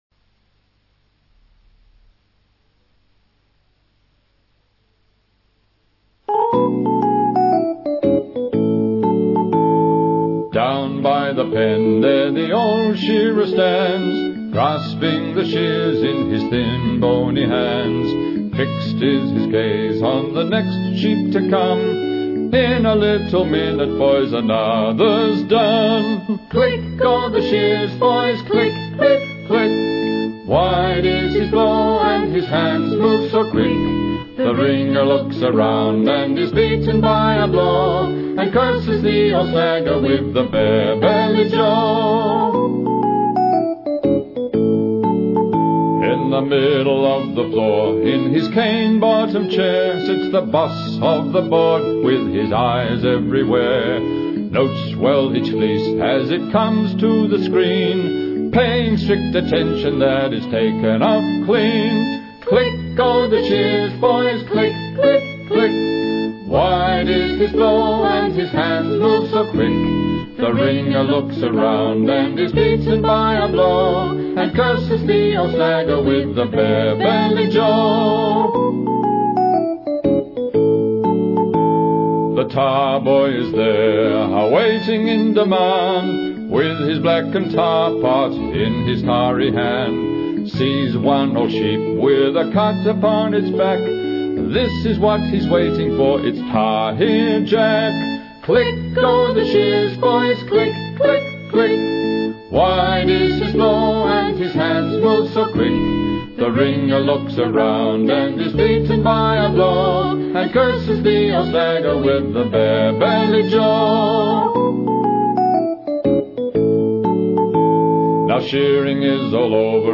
Click Go the Shears 羊毛剪子喀嚓嚓澳大利亚）  vocal   more
"Click Go the Shears"  is a traditional Australian folk song. The song details a day's work for a sheep shearer in the days before machine shears.